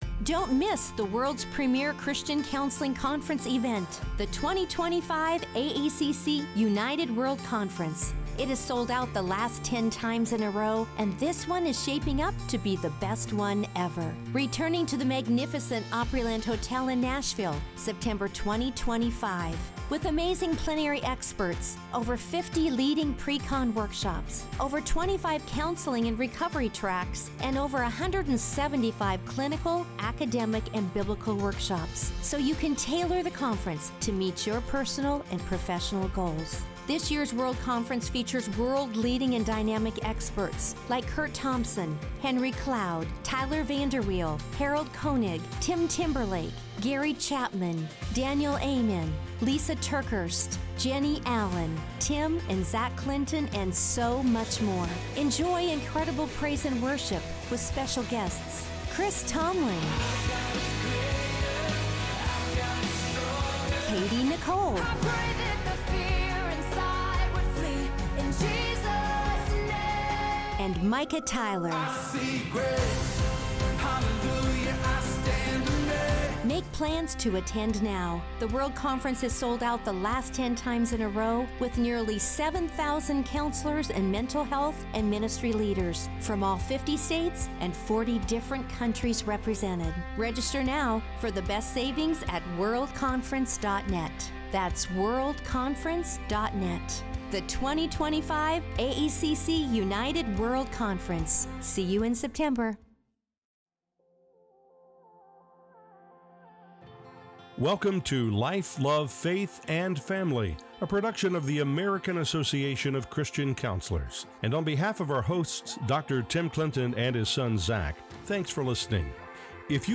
engages in a vulnerable conversation